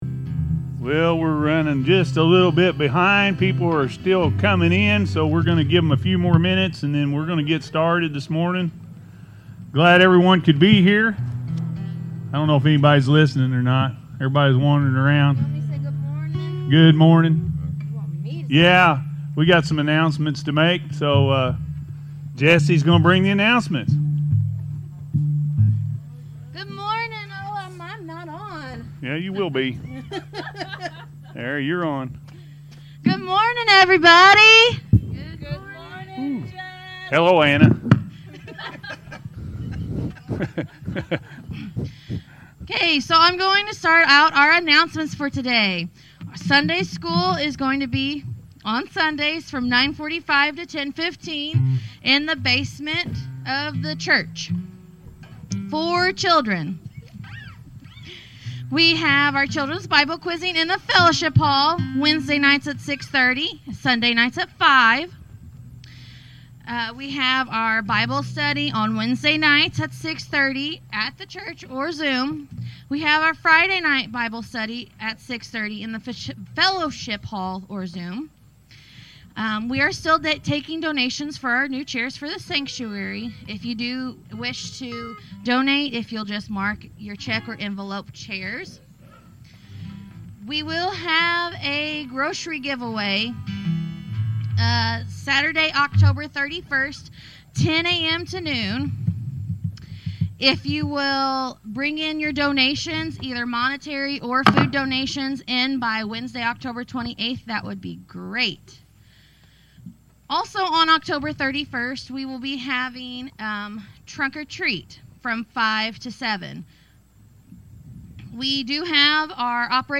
Wait On The Lord-A.M. Service